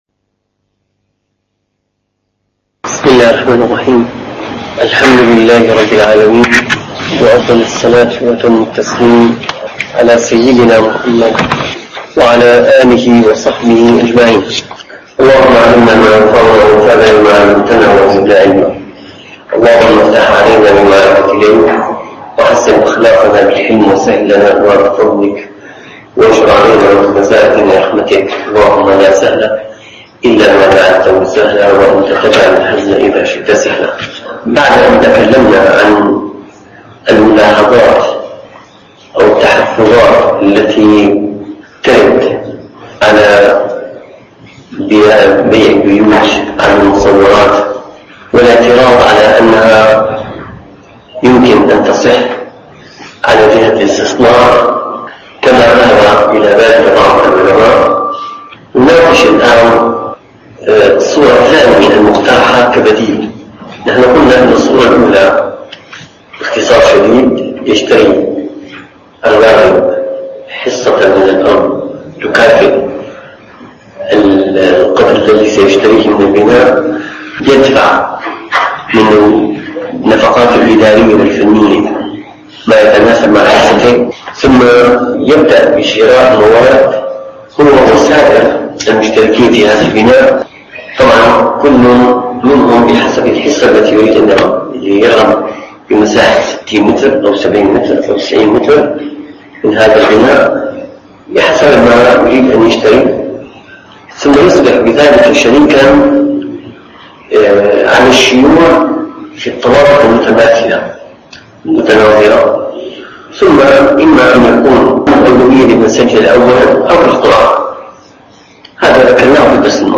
المعاملات المالية المعاصرة - - الدروس العلمية - الفقه الإسلامي - بيع البيوت على المصورات: الصورة الثانية (189-193) + تتمة في بيع الثمار المتلاحقة الظهور (193) + أثر شرط العلم بالمبيع (194)